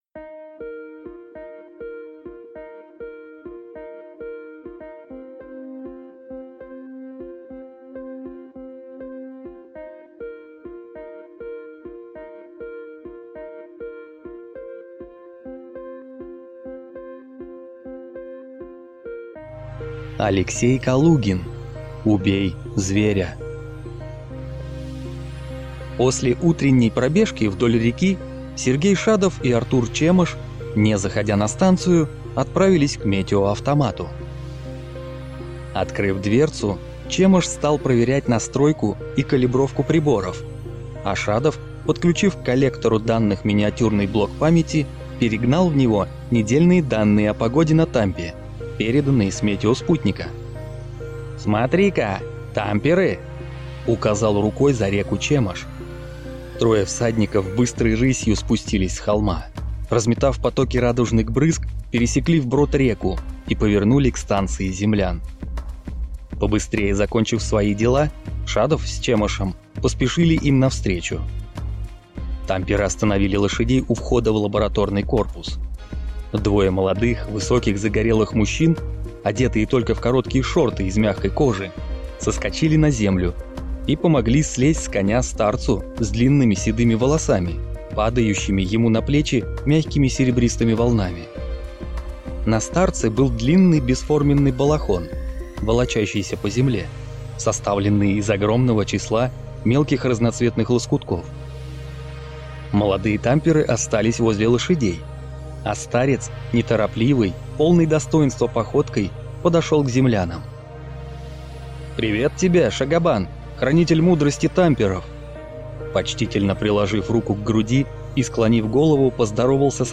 Аудиокнига Убей зверя | Библиотека аудиокниг
Прослушать и бесплатно скачать фрагмент аудиокниги